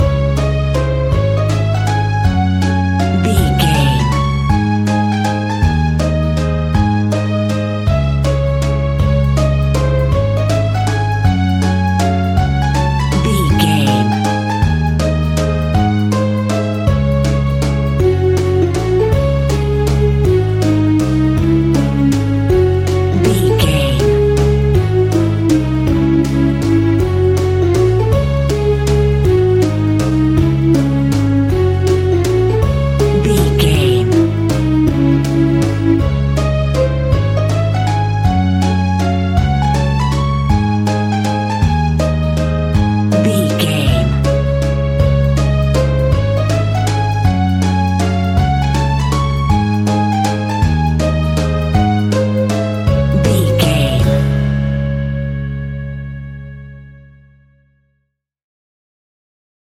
Aeolian/Minor
C#
instrumentals
childlike
cute
happy
kids piano